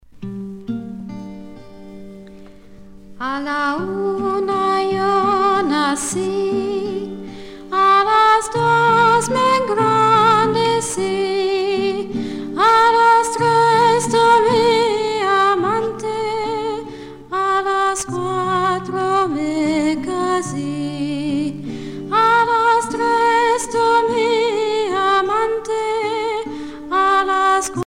Chants et danses des pionniers